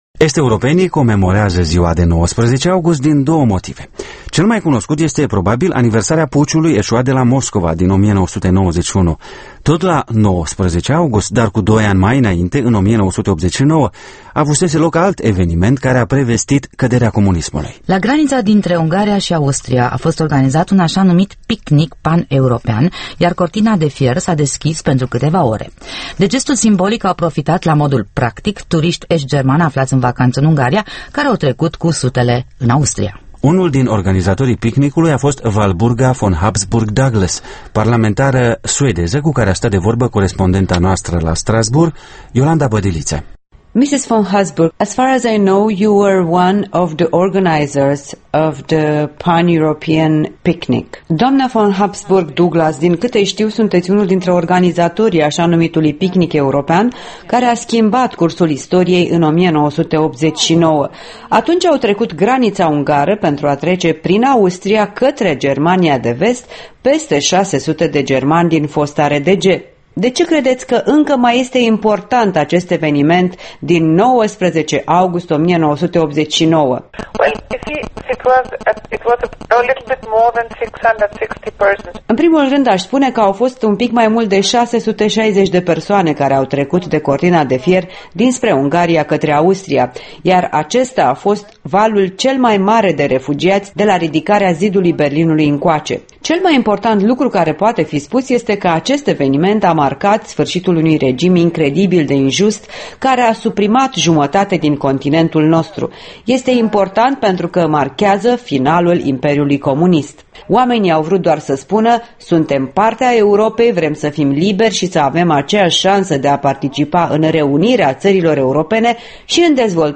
Un interviu cu unul din organizatorii evenimentului din 19 august 1989, parlamentara suedeză Walburga von Habsburg Douglas.